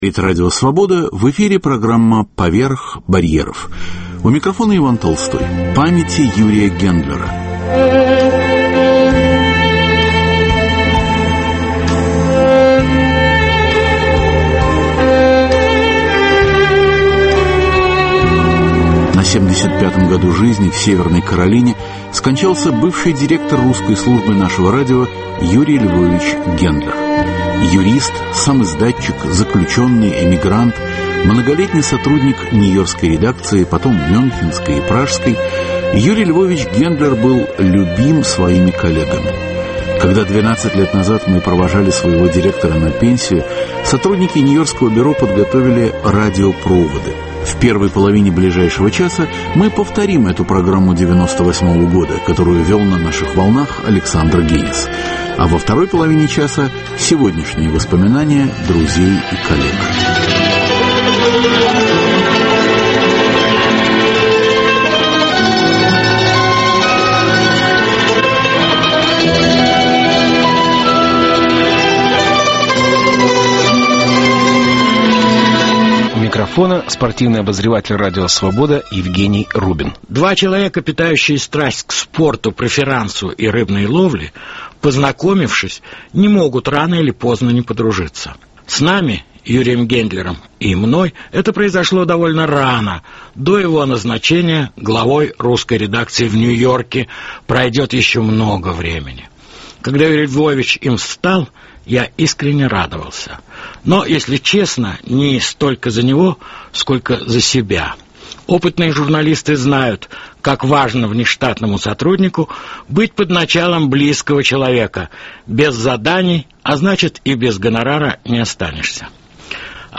Один час в архиве Свободы. В звуковом архиве Радио Свобода - более 30 тысяч программ.